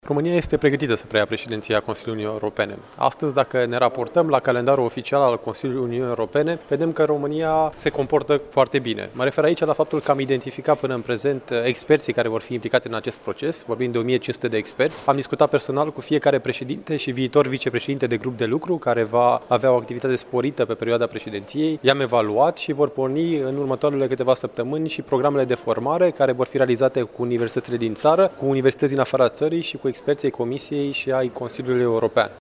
El a participat la dezbaterea cu tema Dezvoltarea Regională şi Reforma Politicii de Coeziune post 2020, organizată de Universitatea Al. I Cuza din Iaşi.